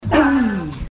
download and share bass